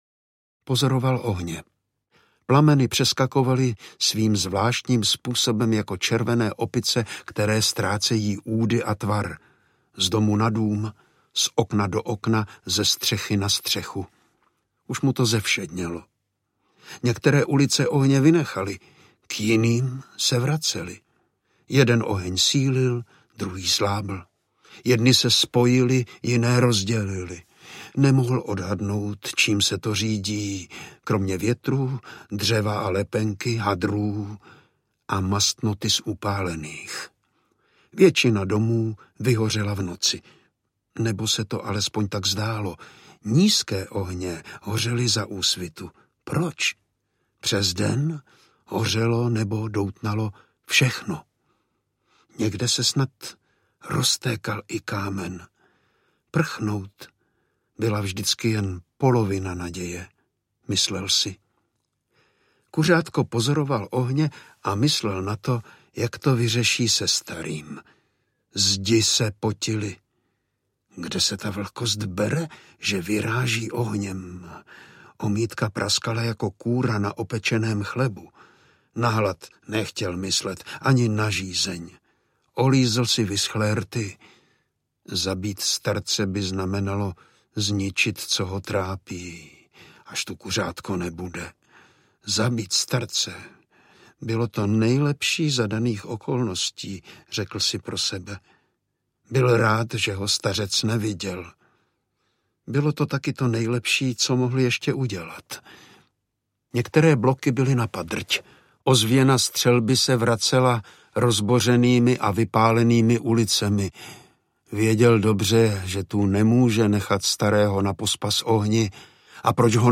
Démanty noci audiokniha
Ukázka z knihy
Vyrobilo studio Soundguru.